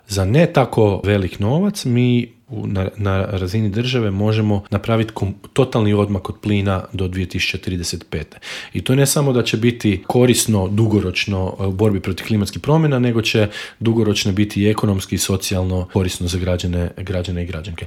Intervju Media servisa